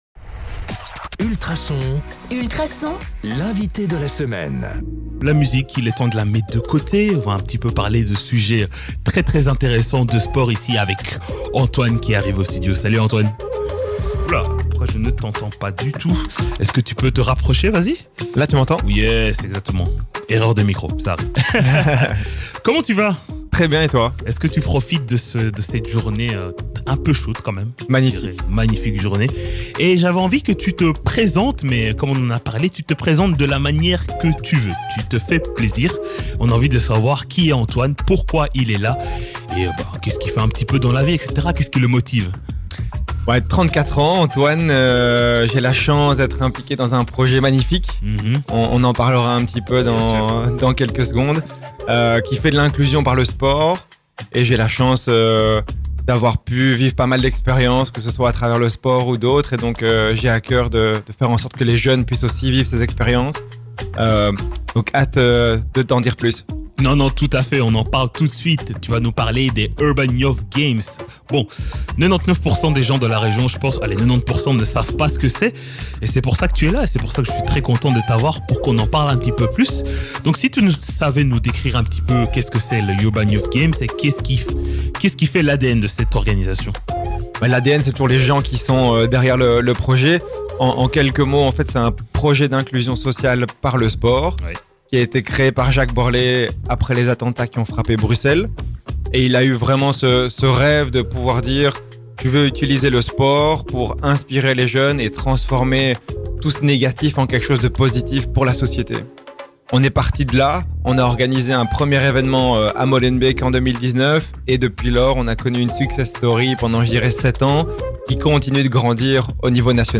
Dans cette conversation inspirante, on parle de : L’ADN des Urban Youth Games Comment créer un environnement de sport inclusif Les valeurs enseignées par le sport L’importance des bénévoles Vue sur l’edition à Wavre le 24 avril Écoutez en famille et discutez-en avec vos enfants !